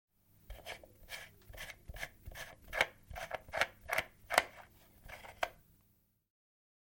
На этой странице собраны звуки электронных сигарет: шипение, бульканье, парение и другие эффекты.